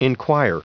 Prononciation du mot enquire en anglais (fichier audio)
Prononciation du mot : enquire